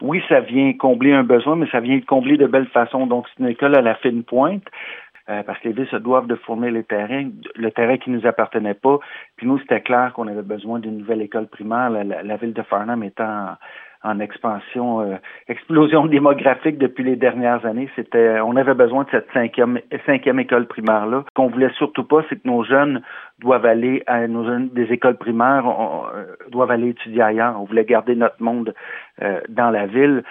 Patrick Melchior, maire de Farham, était présent aujourd’hui,
Lucioles-clip_Patrick-Melchior-extrait.mp3